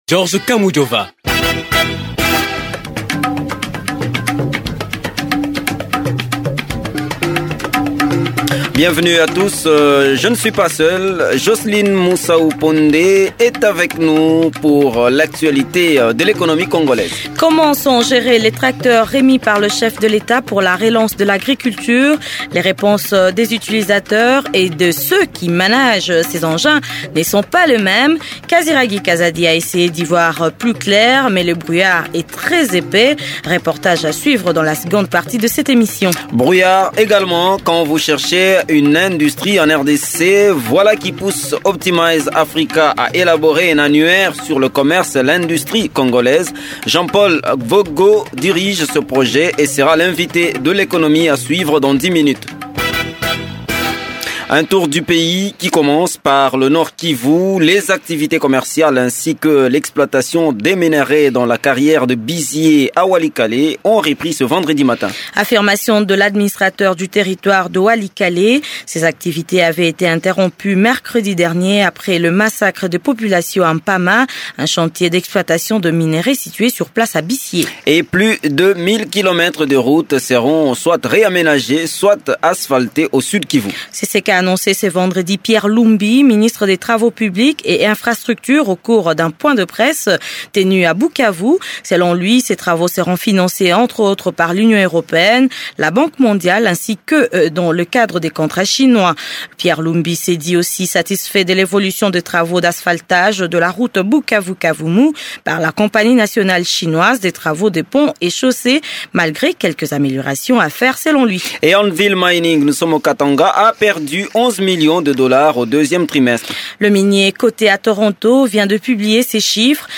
Reportage à suivre dans cette émission.